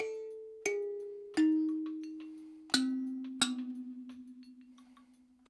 88-PERC8.wav